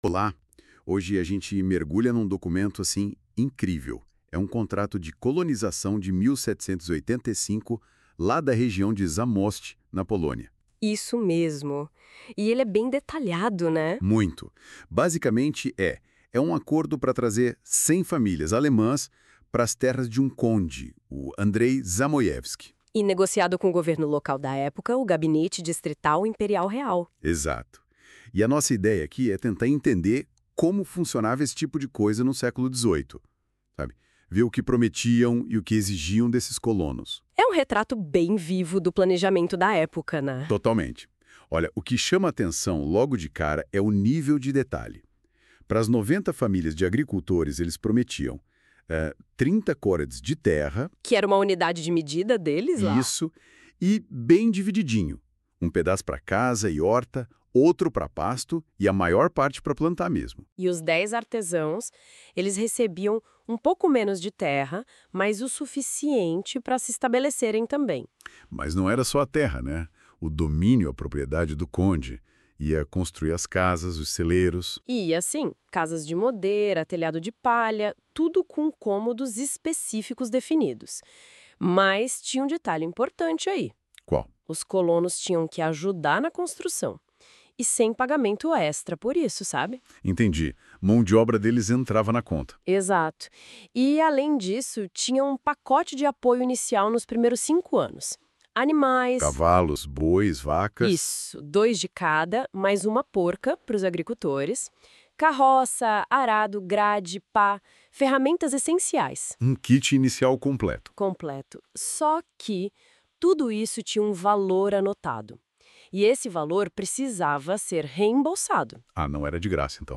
Resumo criado com NotebookLM Pro (Google) on 02.07.2025
• Resumo em áudio (podcast) em português.